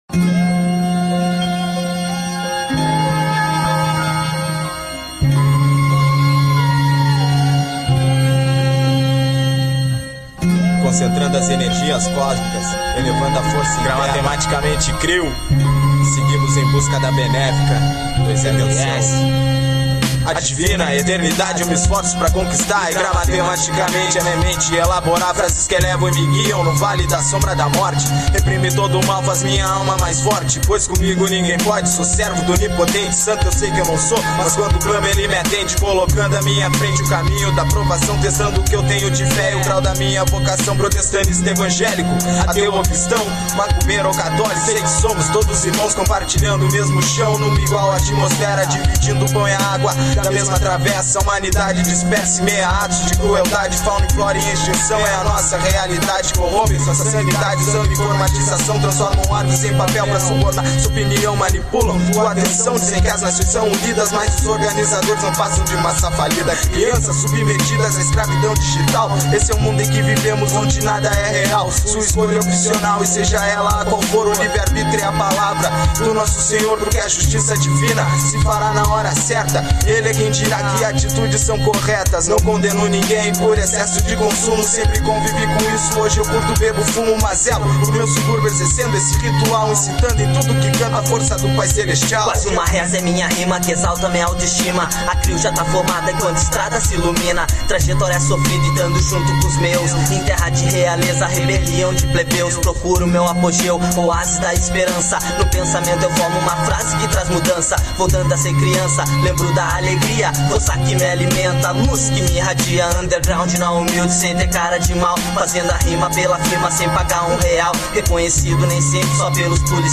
Alternativo